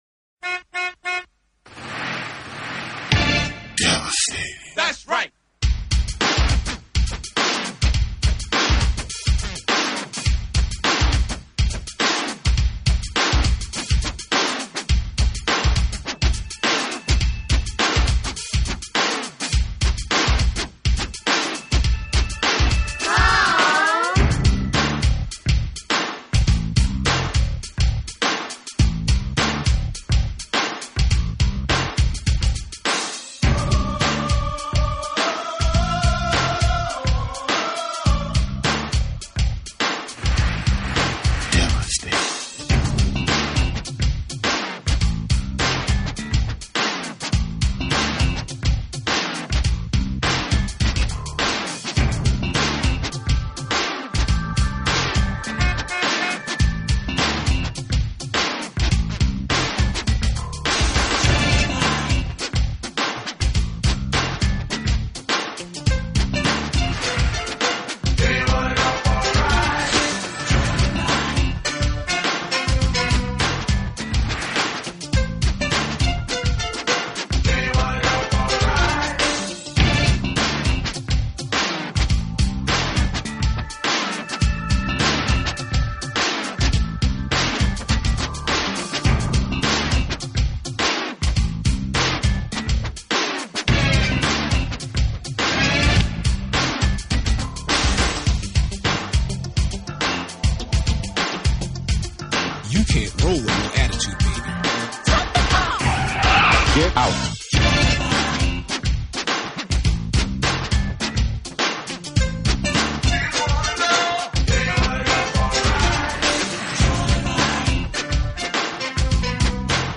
所走的是美国主流器乐演奏路线，当然也是非常主流化的Smooth Jazz。
舒缓人心的乐曲。
立基础。柔情似水的演出与略带Funk的曲风，让他们在乐界赢得不错的口碑，证明了当时